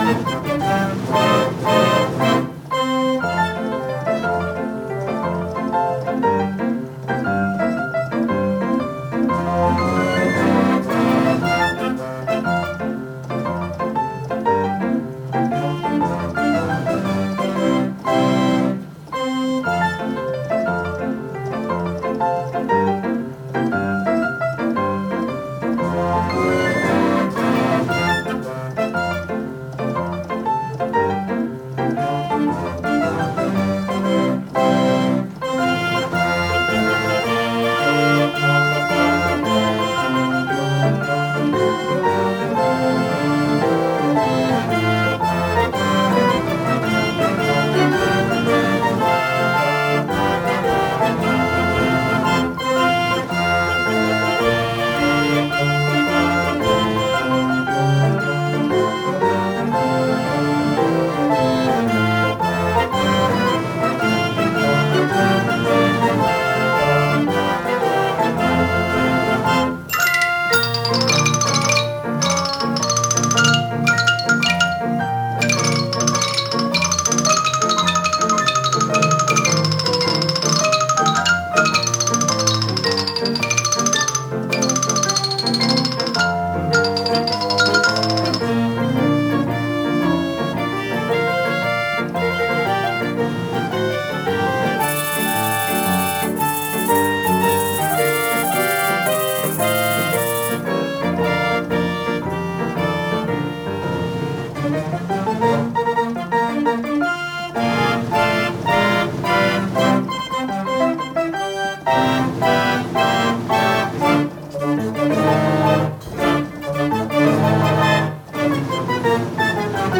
New Zealand Photoplayer Restoration Progress